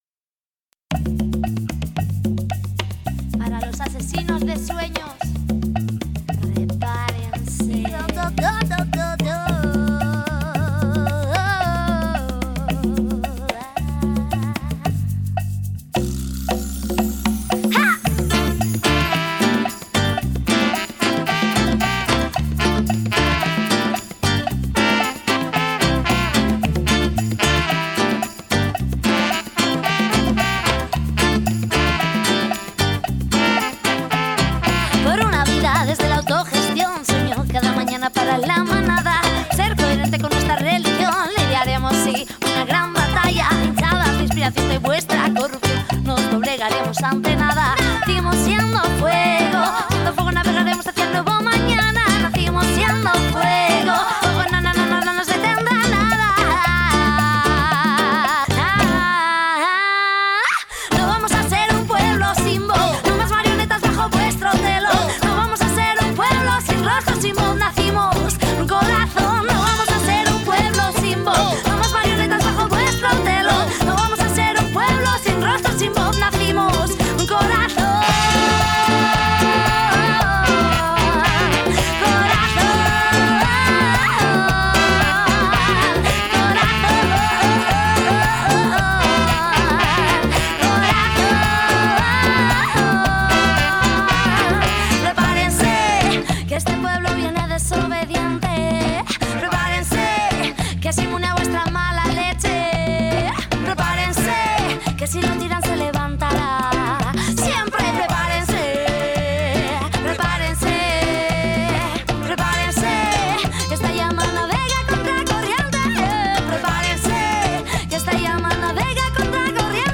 mestizaia, rumba, salsa …,…